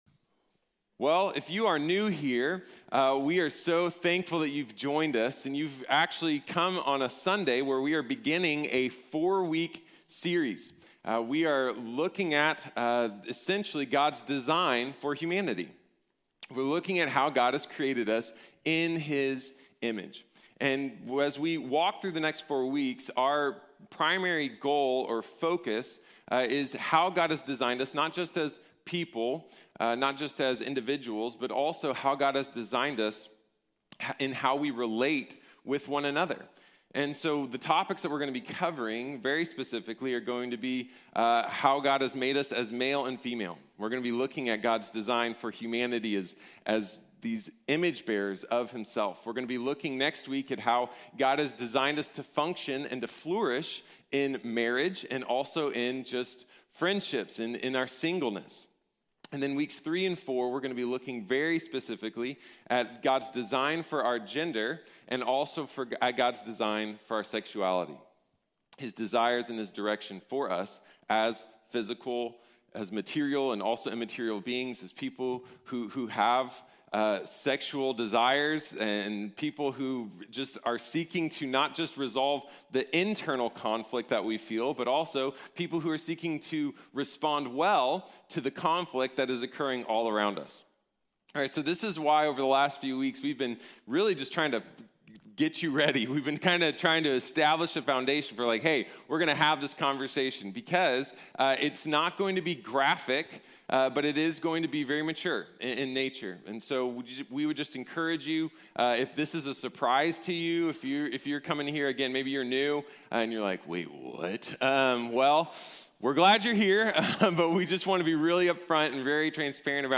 God & Humanity | Sermon | Grace Bible Church